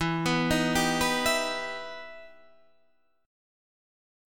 EmM7 chord